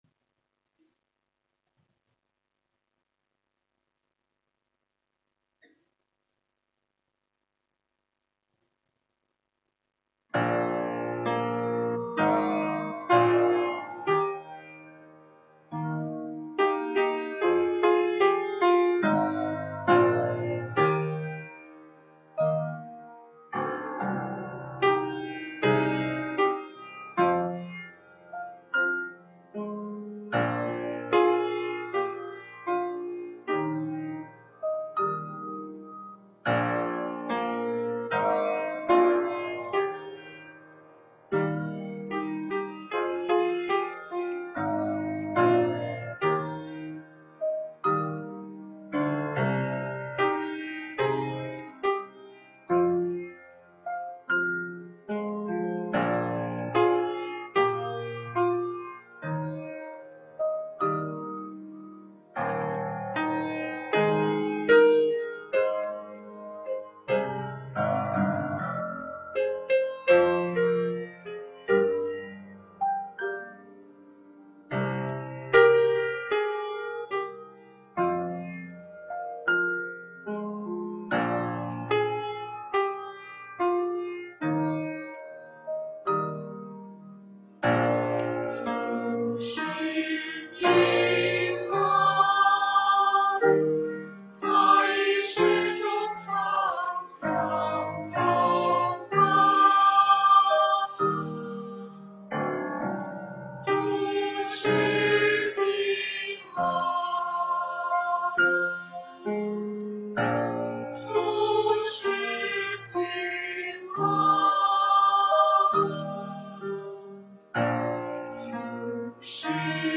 北京基督教会海淀堂